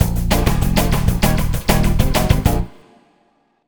Swinging 60s 1 Full-A#.wav